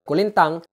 Audio file of the word "Arch Psaltery"